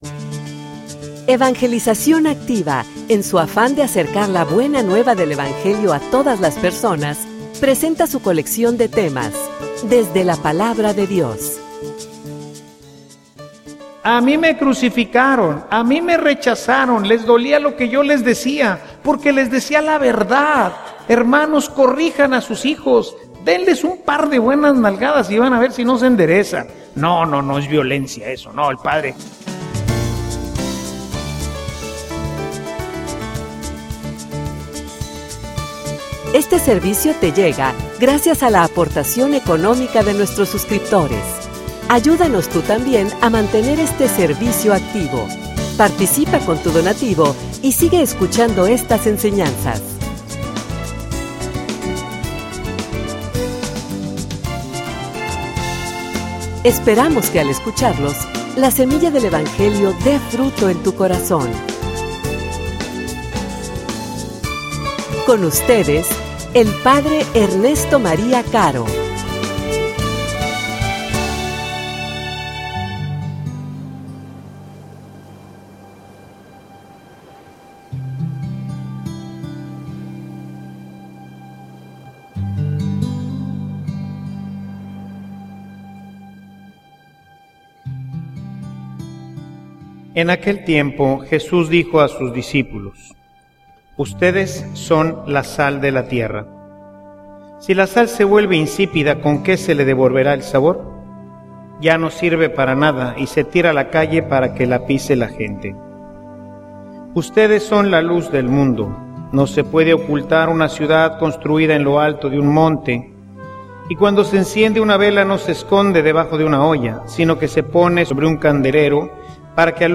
homilia_Un_compromiso_con_el_mundo.mp3